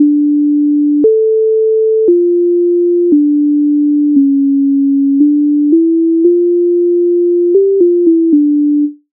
MIDI файл завантажено в тональності d-moll